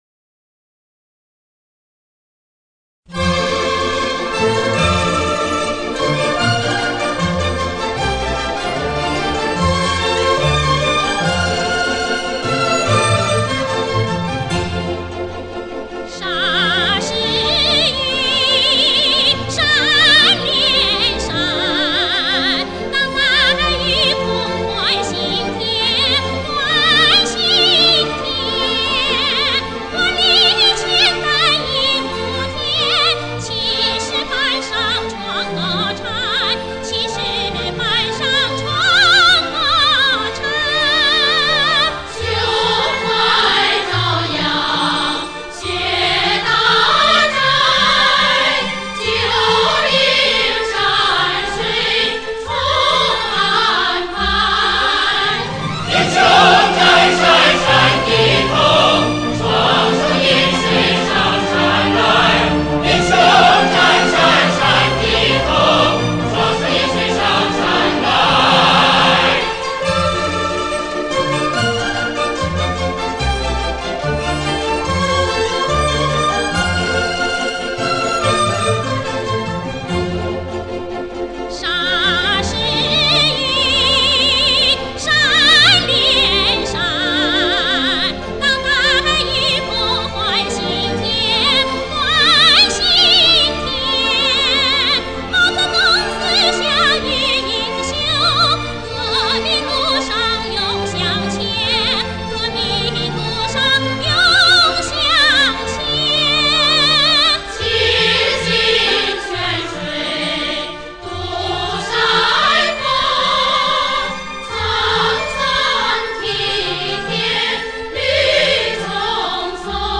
旋律的欢快流畅